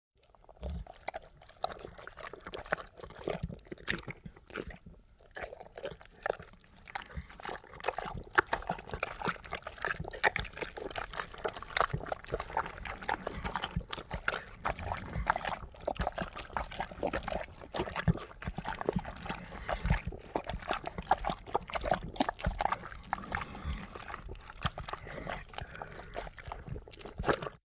creepy-walking-sound